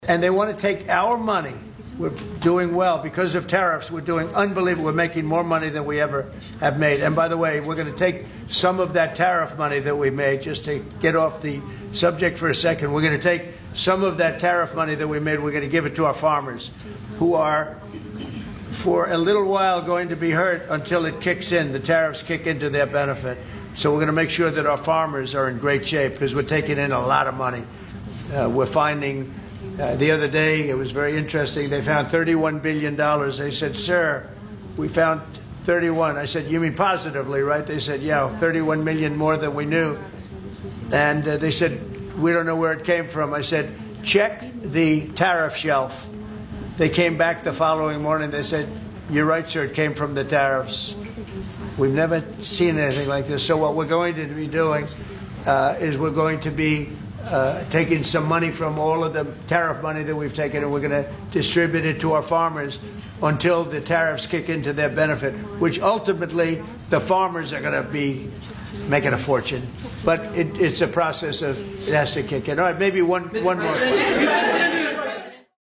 Trump made those statements to the press in the Oval Office on Thursday while hosting Turkish President Recep Tayyip Erdoğan.